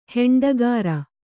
♪ heṇḍagāra